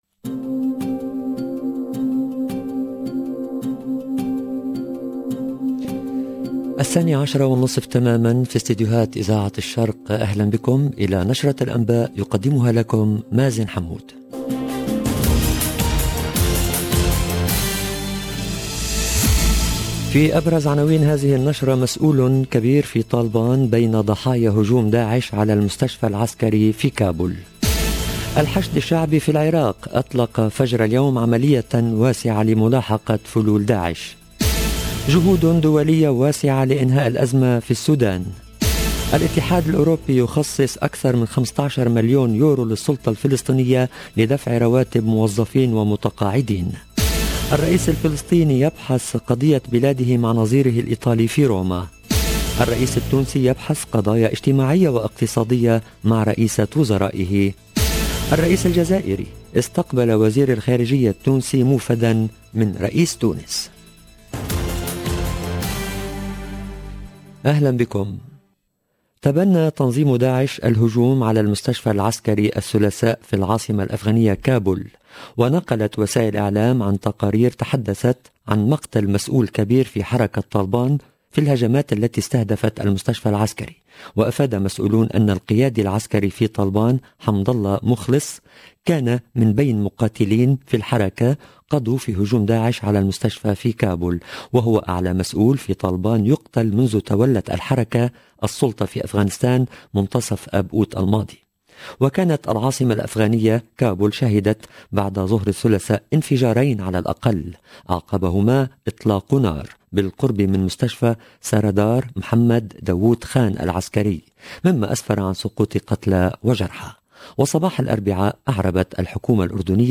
EDITION DU JOURNAL DE 12H30 EN LANGUE ARABE